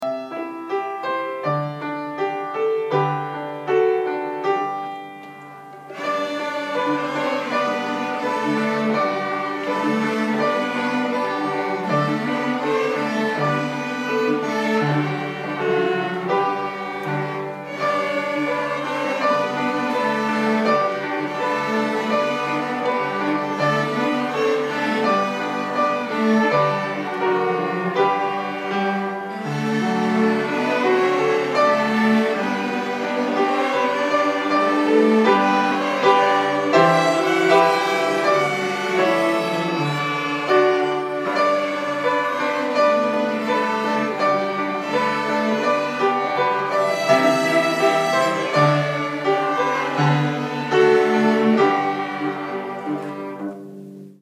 Orchestra Strings - Deck The Halls